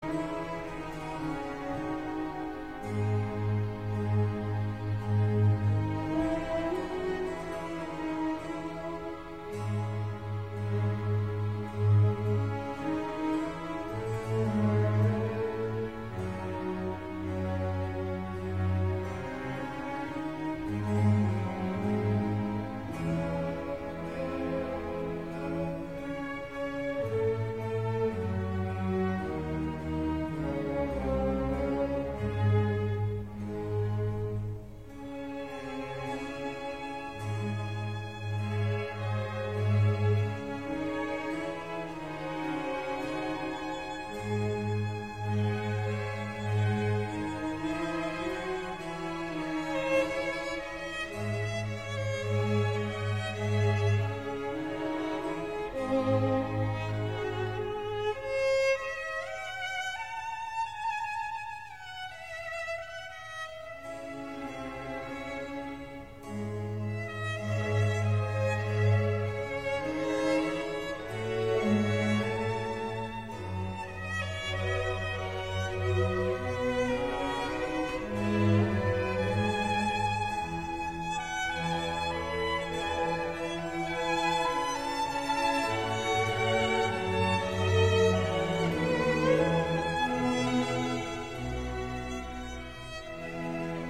* Johann Sebastian Bach – Adagio from Concerto No. 2 in E major for Violin, BWV 1042